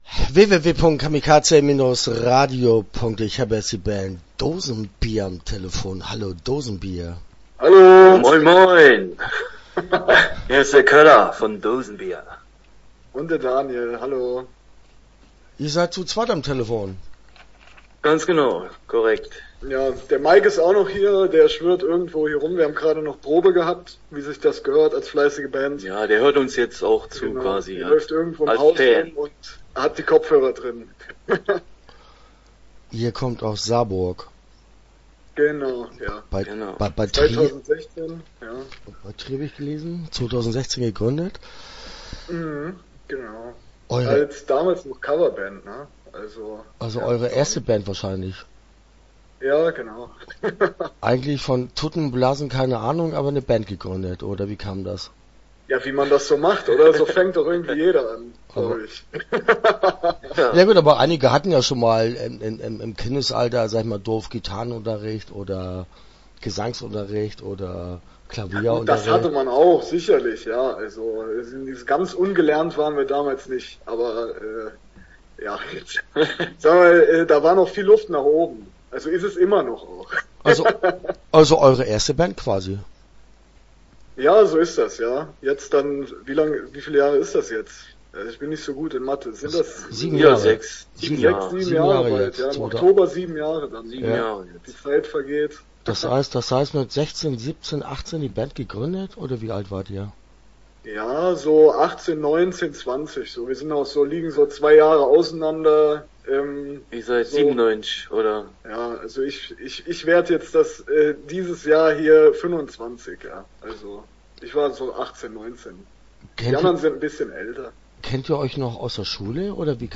Dosenbier - Interview Teil 1 (11:39)